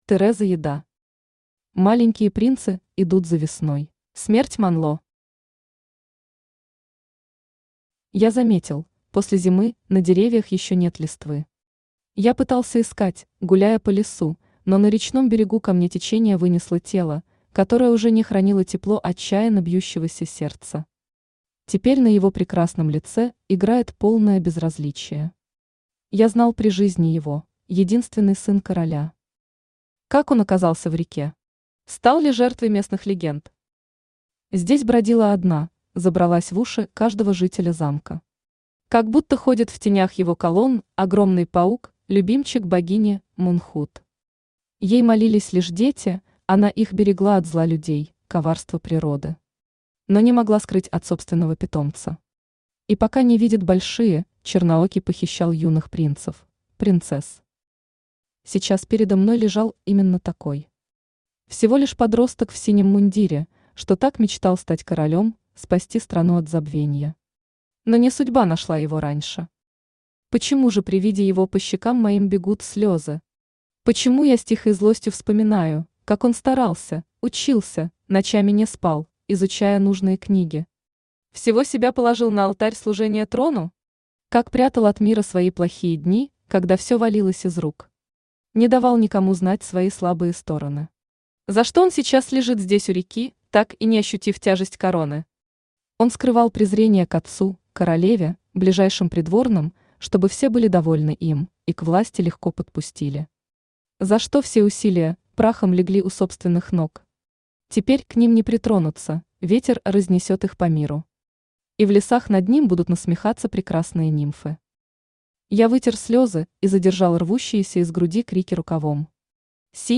Aудиокнига Маленькие принцы идут за весной Автор Тереза Еда Читает аудиокнигу Авточтец ЛитРес.